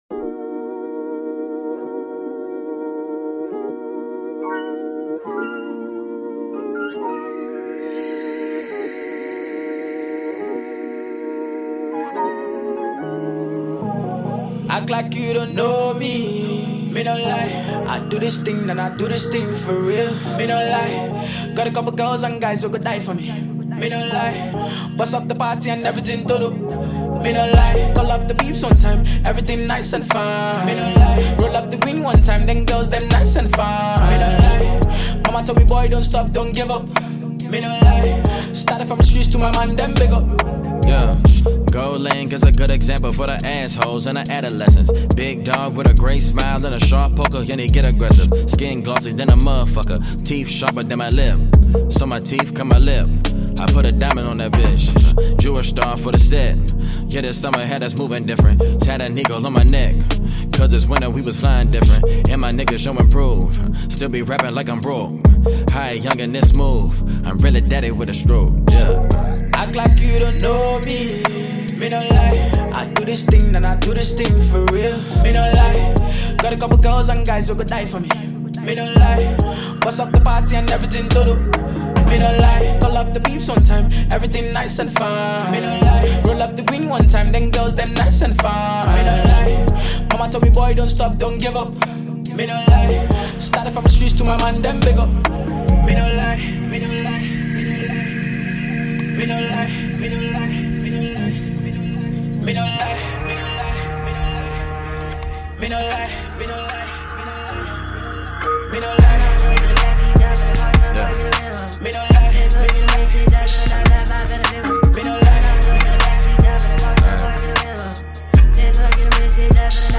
American rapper
hip-hop